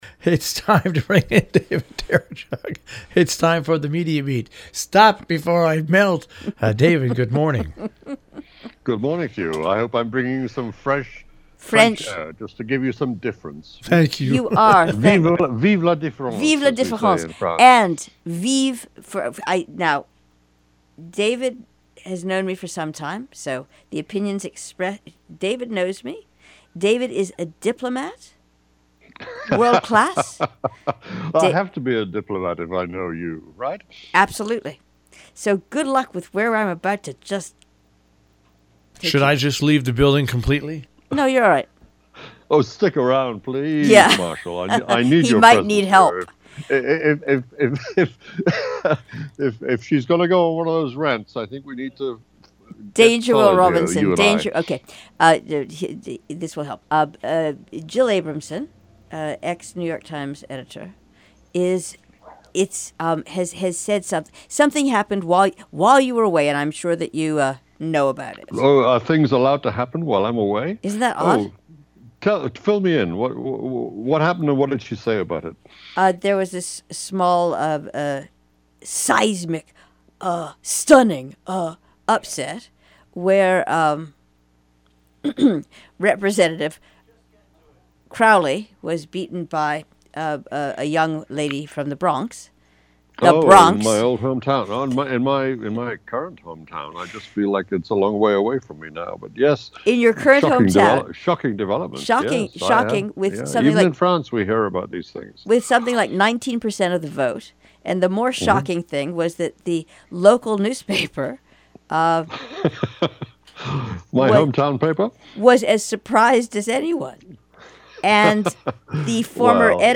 The Media Beat Reporting Live in France June 29!